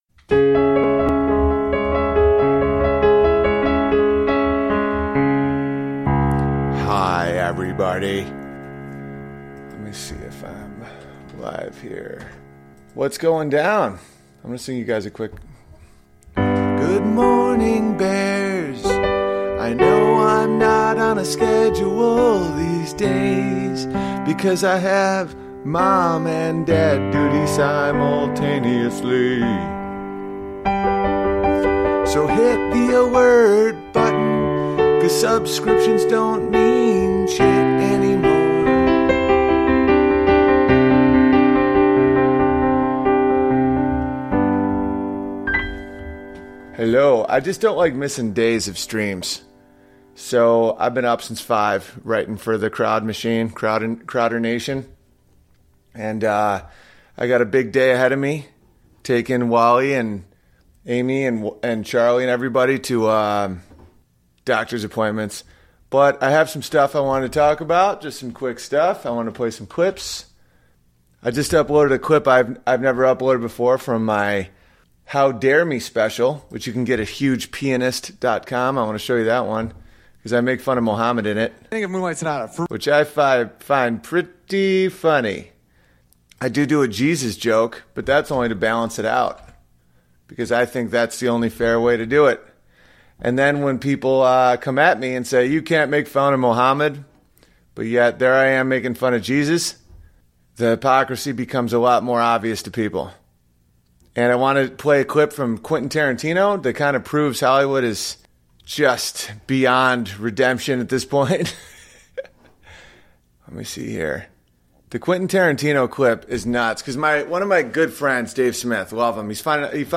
A clip of Quinton Tarantino on Howard Stern defending Roman Polanski raping a child.
Piano, piano and more piano in this episode.